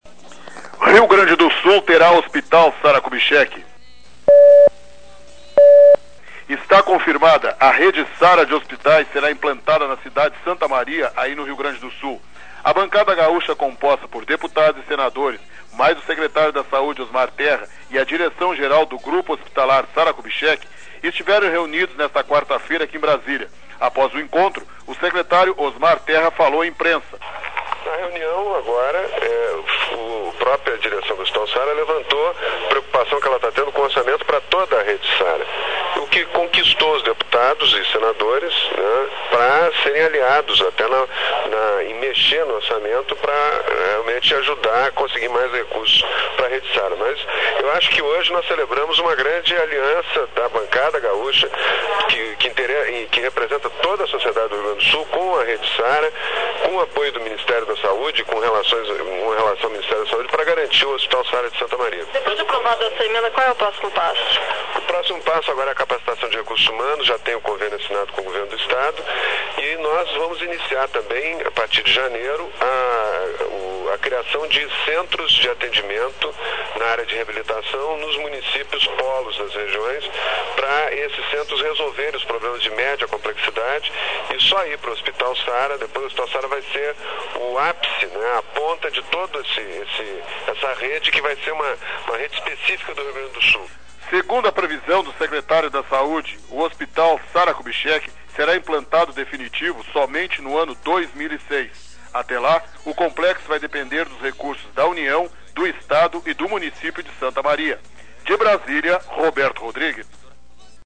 Rio Grande do Sul terá Hospital Sarah Kubitschek (sonora: secretário da Saúde Osmar Terra)Local: Brasília-DFDuração: 00:01:56